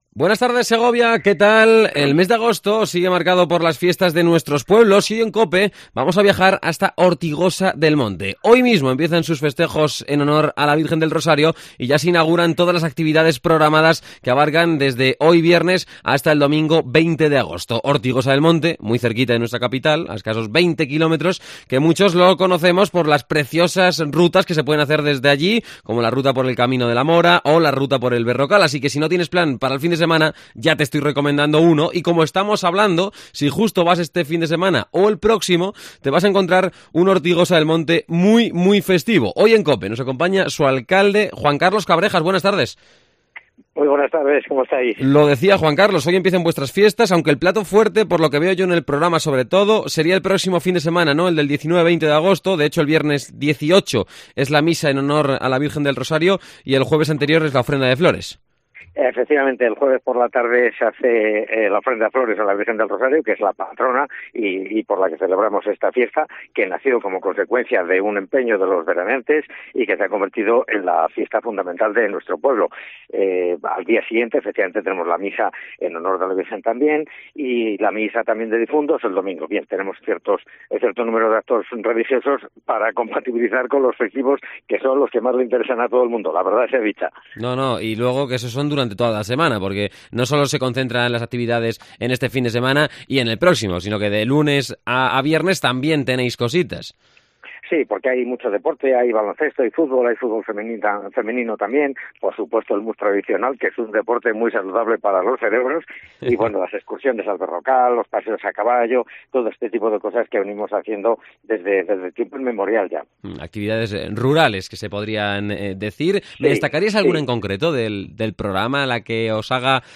Entrevista a Juan Carlos Cabrejas, Alcalde de Ortigosa del Monte